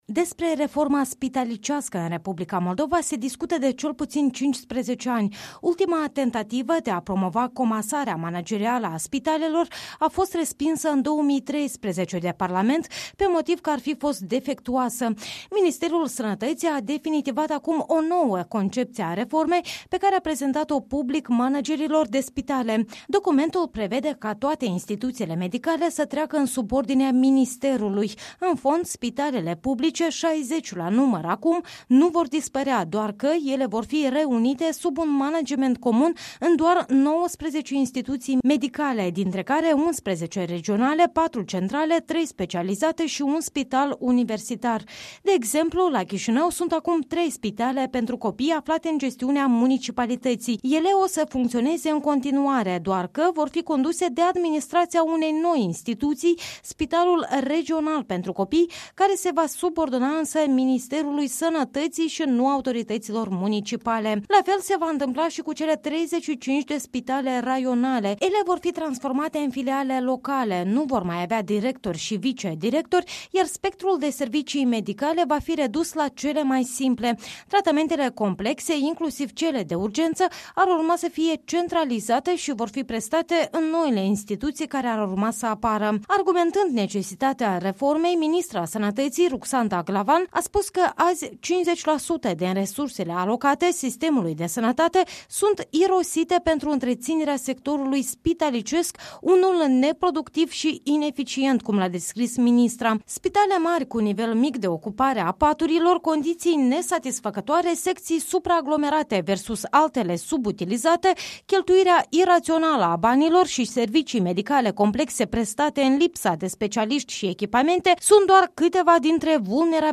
Ministrul sănătății, Ruxanda Glavan, a făcut publice la o conferință de presă detalii despre reforma spitalelor – una din cele controversate dintr-un domeniu în care orice mișcare stârnește mereu – și nu numai în Moldova – dispute dintre cele mai intense.